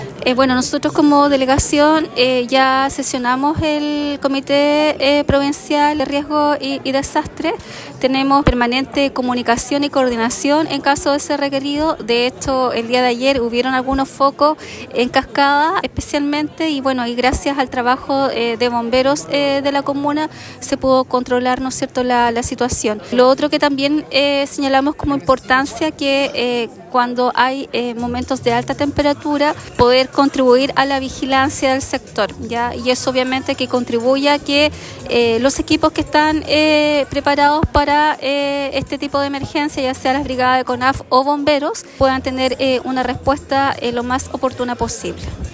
Finalmente, Pailalef destacó que el Comité de Gestión del Riesgo de Desastres Provincial mantiene una coordinación exhaustiva entre brigadas de CONAF, Bomberos, Fuerzas Armadas, y equipos de seguridad, con el objetivo de responder de manera rápida y efectiva ante cualquier emergencia.